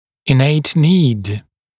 [ɪ’neɪt niːd][и’нэйт ни:д]врожденная потребность